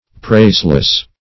praiseless - definition of praiseless - synonyms, pronunciation, spelling from Free Dictionary
Praiseless \Praise"less\, a.